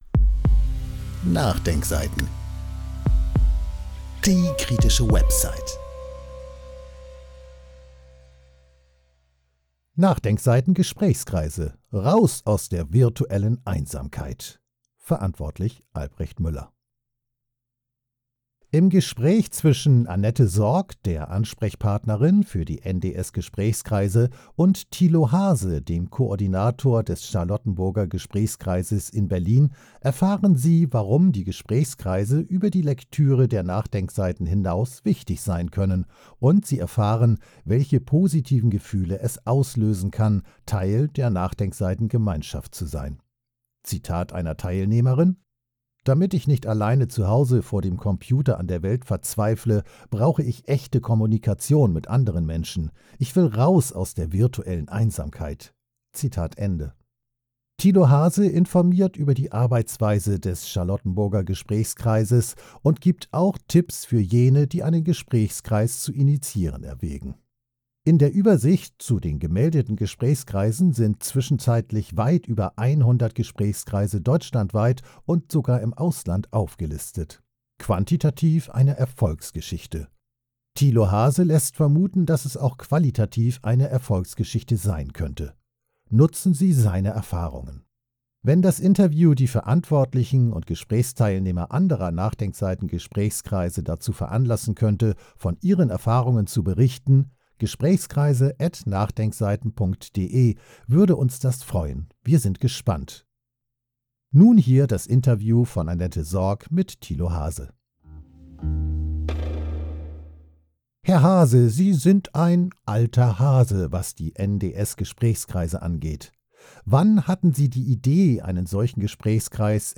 Wenn das Interview die Verantwortlichen und Gesprächsteilnehmer anderer NachDenkSeiten-Gesprächskreise dazu veranlassen könnte, von ihren Erfahrungen zu berichten [email protected] , würde uns das freuen.